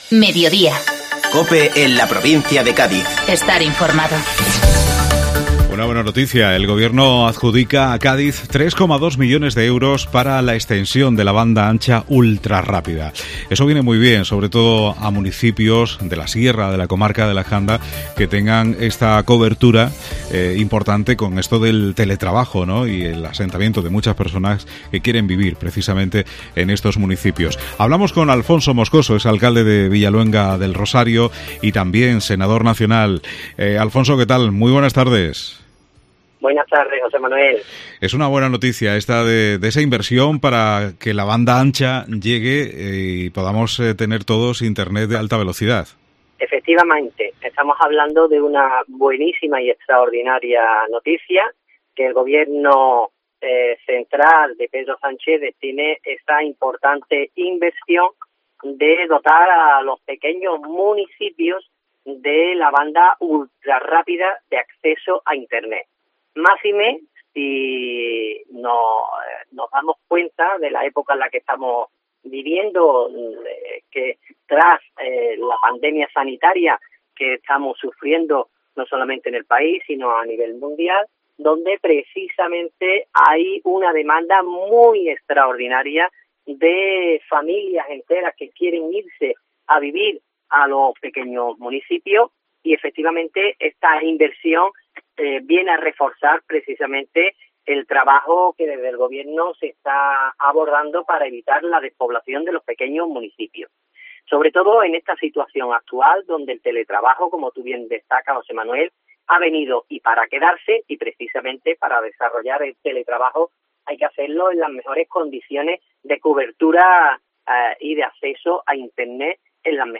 Alfonso Moscoso, Alcalde de Villaluenga del Rosario y Senador Nacional habla de la adjudicación de 3,2 millones de euros para la extensión de banda ancha ultrarrápida en la provincia de Cádiz.